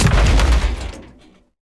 car-crash-2.ogg